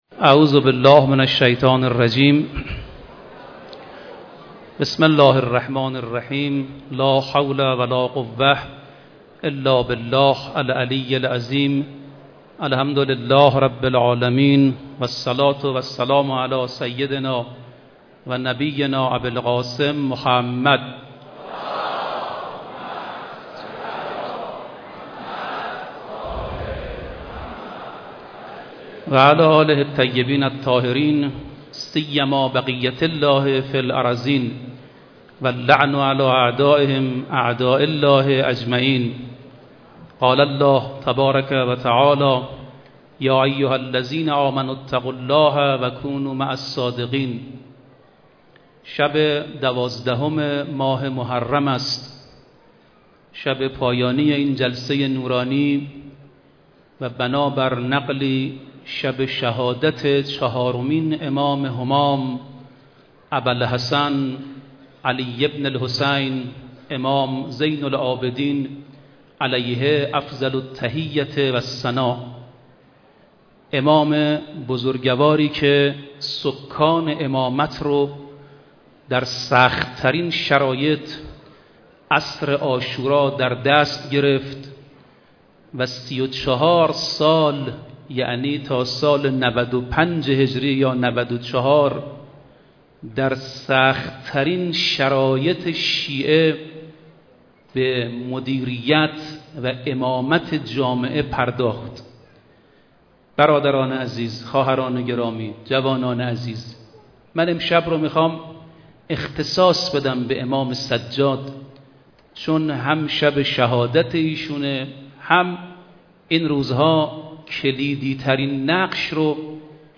پنجمین شب عزاداری حضرت اباعبدالله الحسین (ع) در حسینیه امام خمینی(ره) برگزار شد
سخنرانی